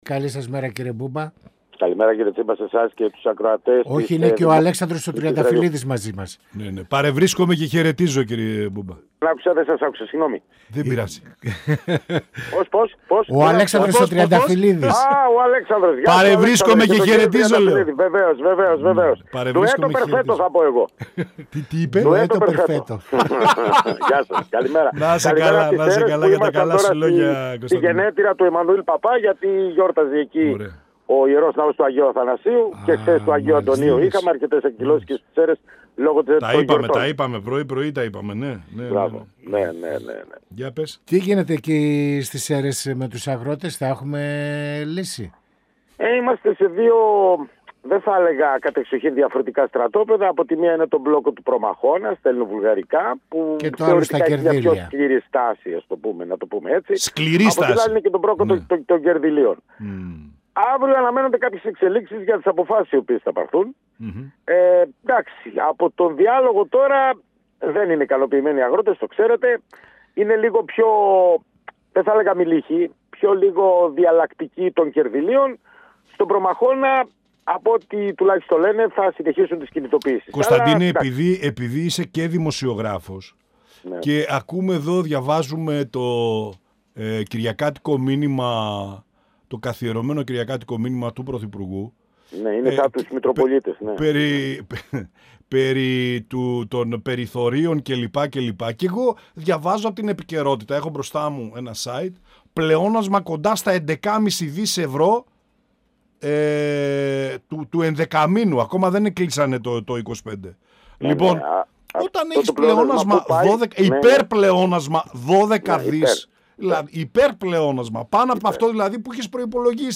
Στα αγροτικά μπλόκα, στο σκάνδαλο του ΟΠΕΚΕΠΕ, αλλά και το πρόβλημα της ακρίβειας σε συνδυασμό με την αγοραστική δύναμη των πολιτών αναφέρθηκε ο Βουλευτής της «Ελληνικής Λύσης» Κωνσταντίνος Μπούμπας, μιλώντας στην εκπομπή «Πανόραμα Επικαιρότητας» του 102FM της ΕΡΤ3.
Συνεντεύξεις